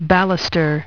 Transcription and pronunciation of the word "baluster" in British and American variants.